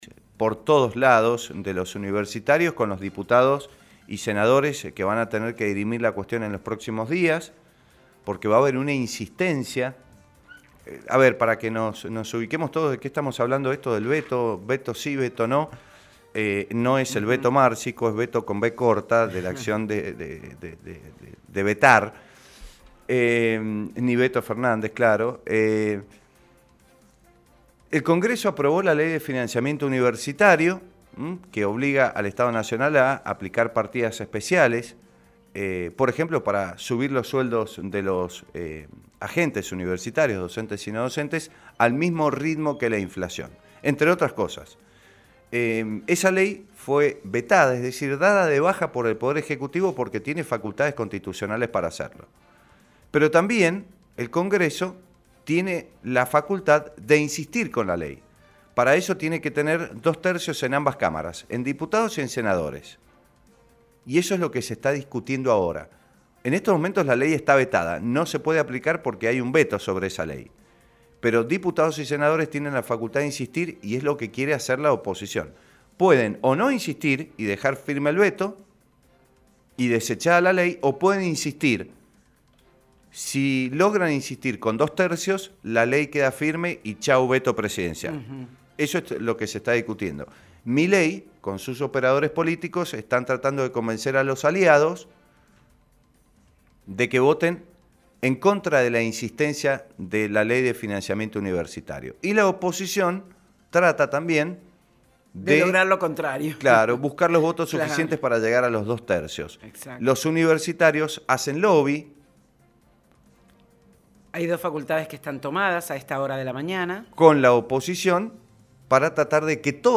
En este contexto, el diputado nacional por San Juan, Walberto Allende, explicó cual será el panorama que se presentará en el debate.